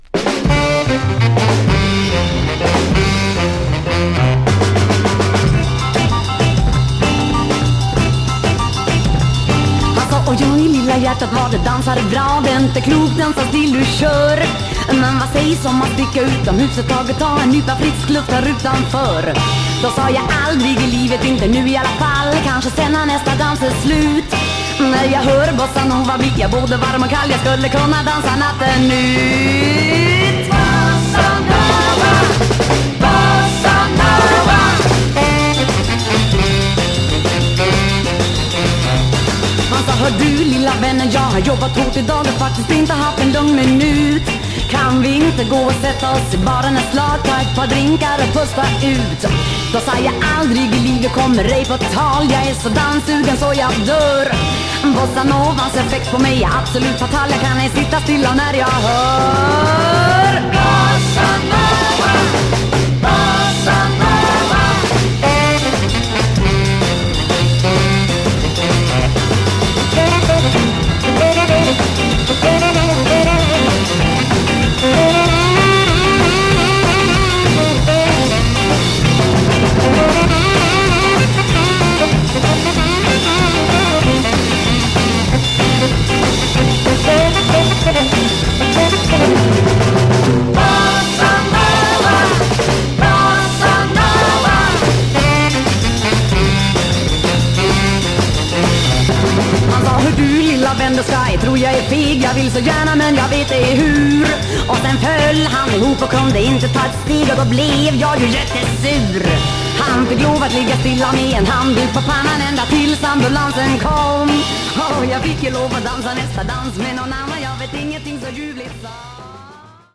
Bossa / Samba sweden
試聴曲は良好です。